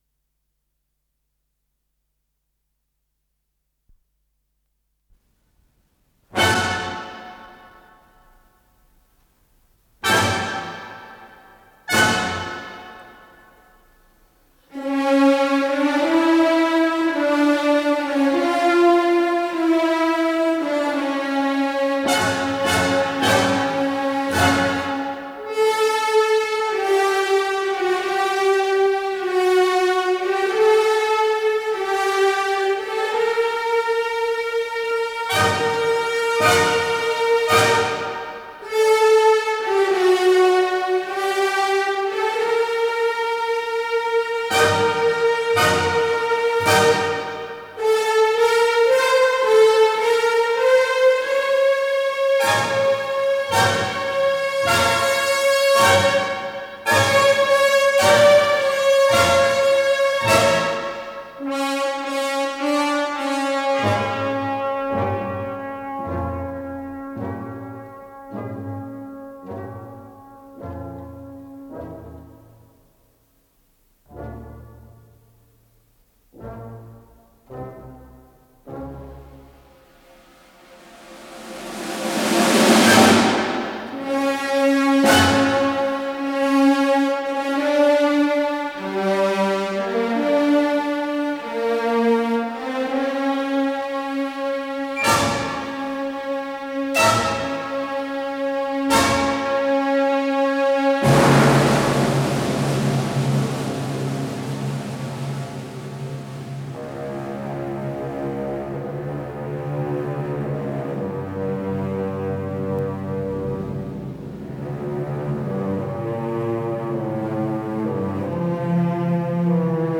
с профессиональной магнитной ленты
Скорость ленты38 см/с
ВариантМоно